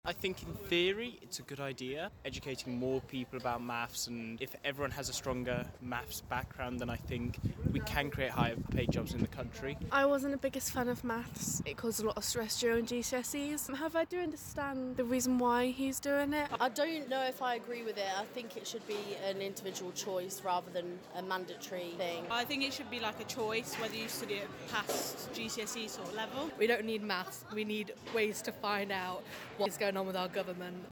DMU students give their thoughts.
Maths-Vox-_mixdown.mp3